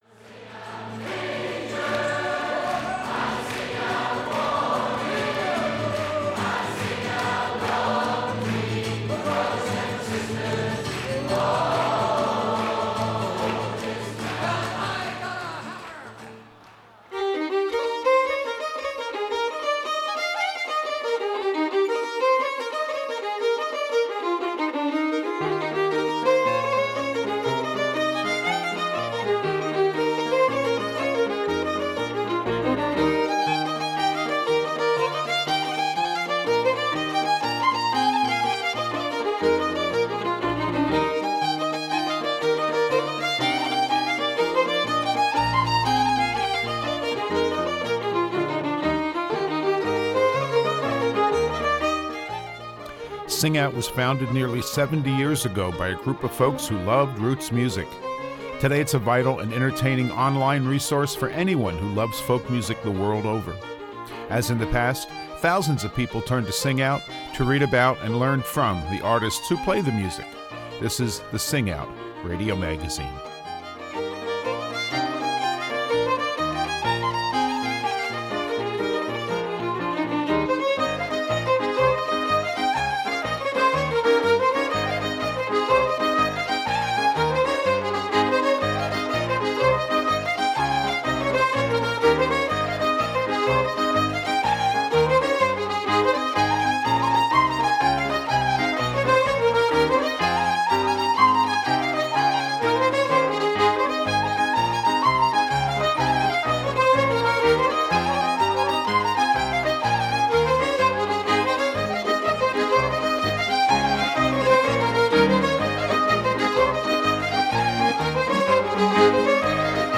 St. Patrick's Day is both a religious holiday and a celebration of Irish history, culture and especially music.